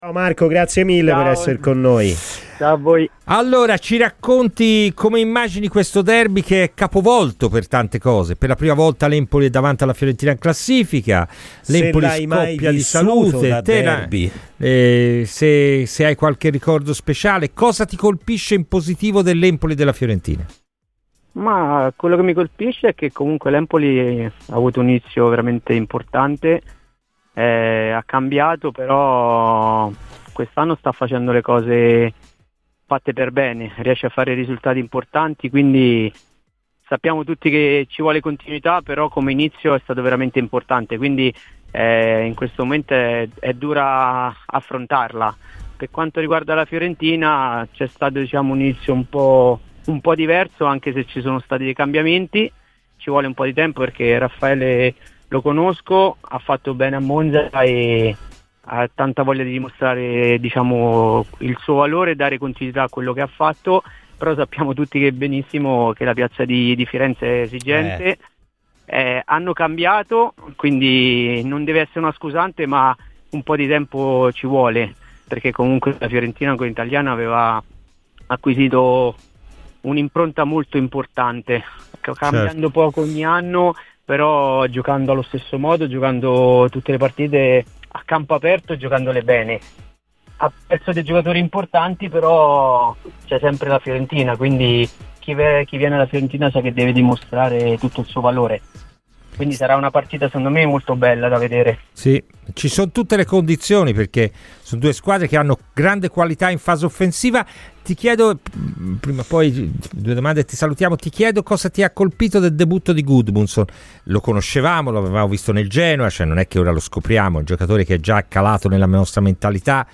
Marco Marchionni, ex centrocampista viola, in diretta su Radio FirenzeViola durante "Palla al Centro" ha dato così le sue impressioni sulla Fiorentina: "Ci sono stati dei cambiamenti.